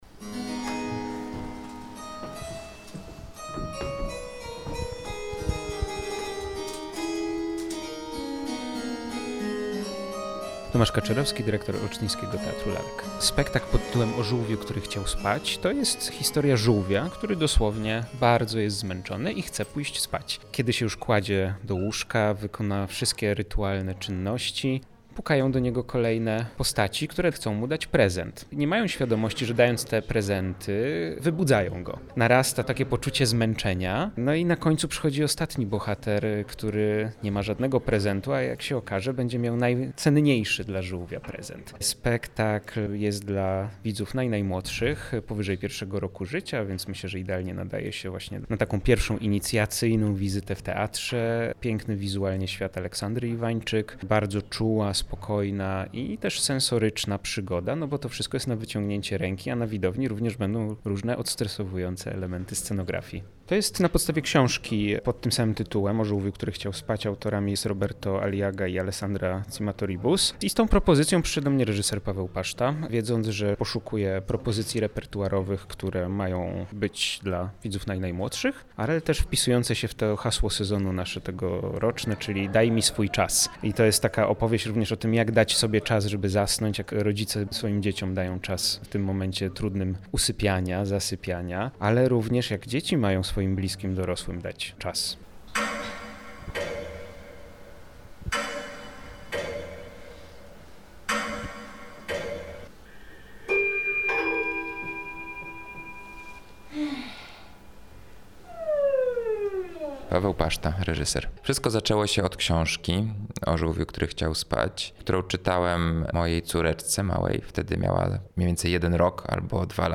Twórcy przedstawienia O żółwiu, który chciał spać opowiadają o pracy z lalką, budowaniu świata sensorycznego i o tym, dlaczego opowieści dla najmłodszych są ważne także dla dorosłych.